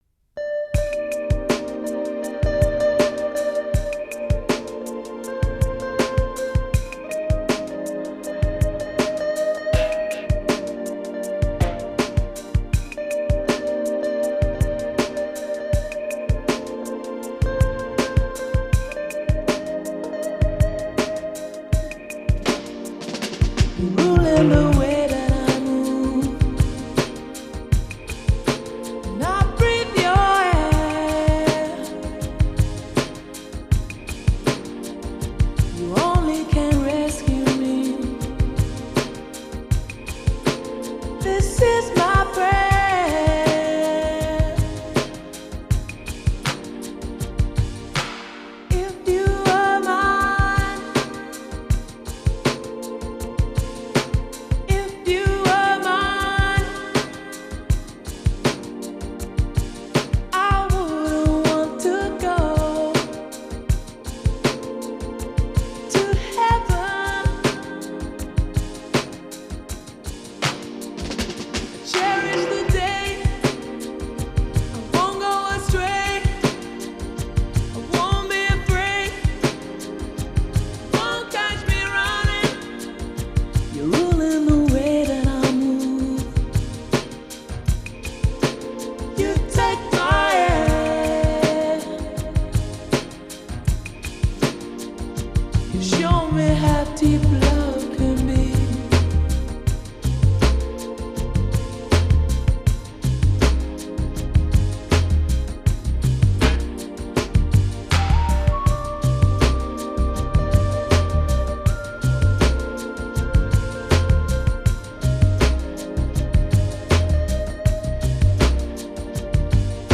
vocals
saxophone / guitar
keyboards
bass